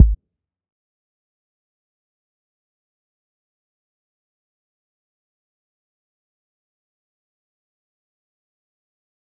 kits/Southside/Kicks/LEX Drum(2).wav at main
LEX Drum(2).wav